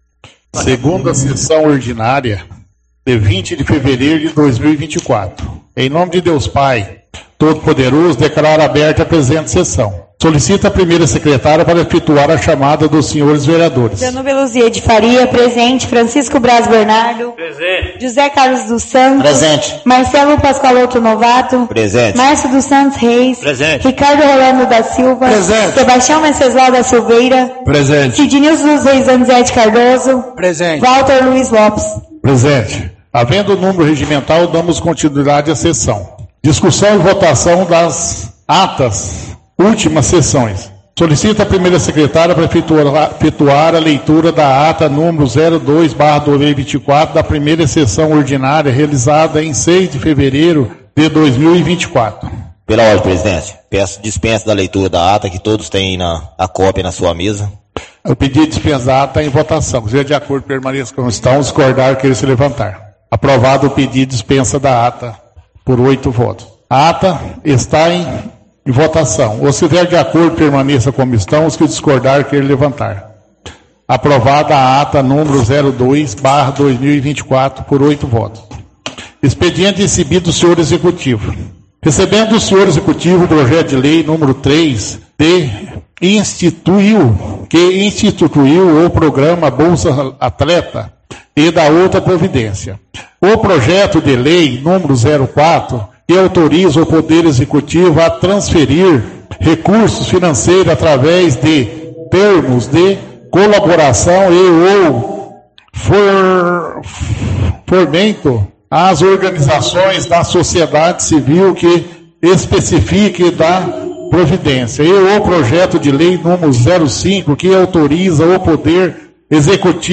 Áudio 2ª Sessão Ordinária – 20/02/2024